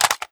sako95_magout_empty.wav